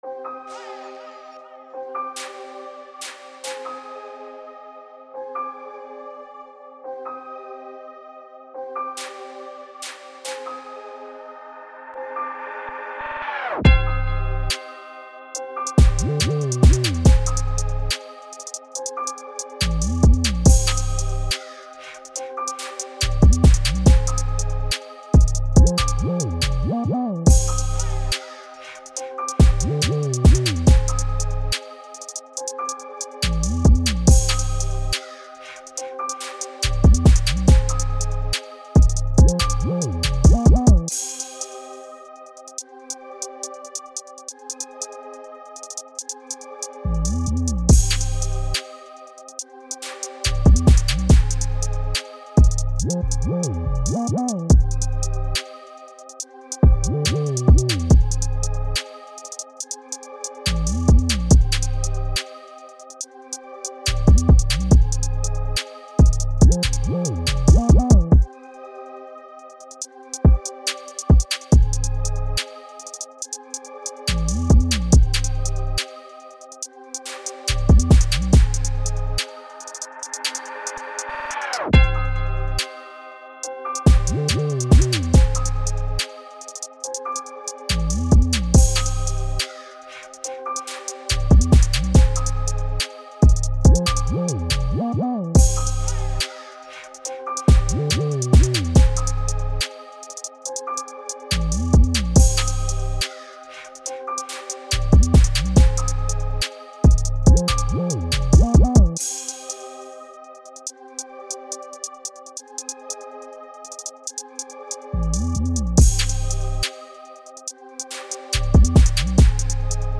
rumbling 808s thumping